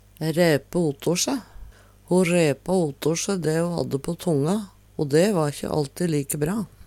DIALEKTORD PÅ NORMERT NORSK ræpe otor se fornærme Eksempel på bruk Ho ræpa otor se dæ ho hadde på tonga, o dæ va`kje allti like bra.